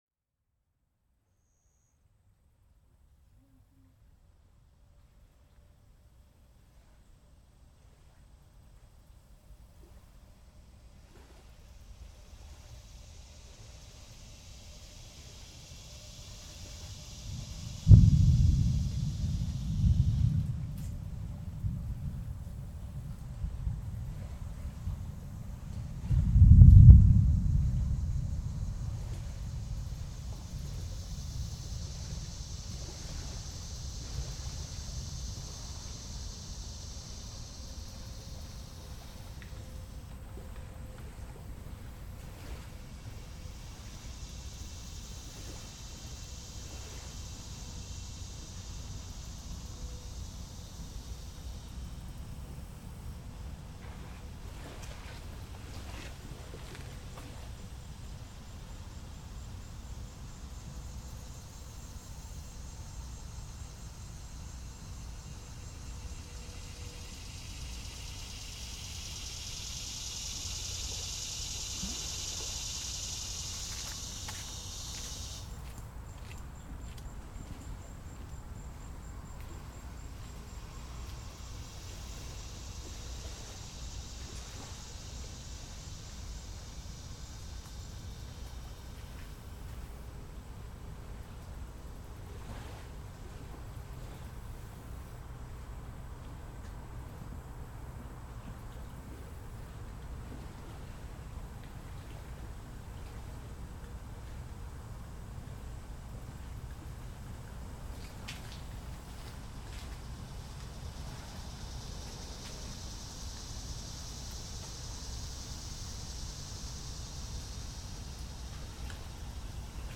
A late afternoon field recording on the banks of Jordan Lake in North Carolina.
lake-jordan-long.mp3